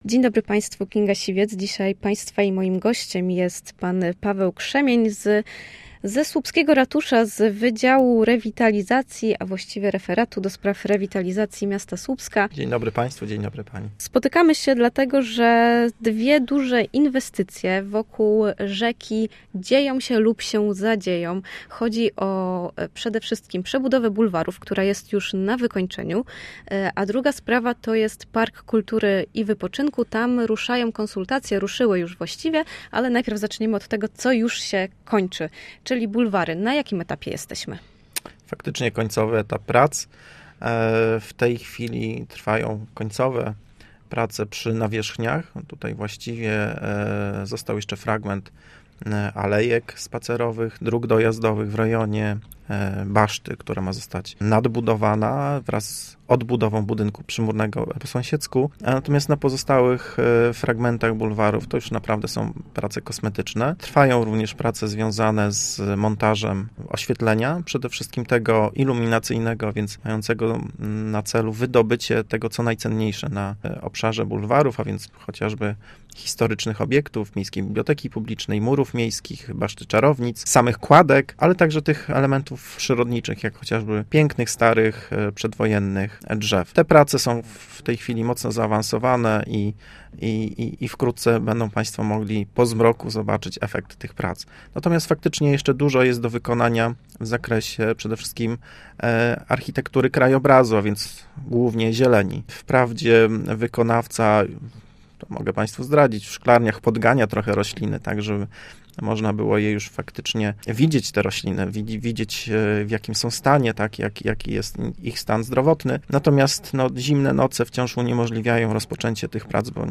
Gość dnia